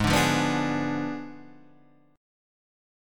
G#dim7 chord {4 5 3 4 3 4} chord